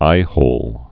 (īhōl)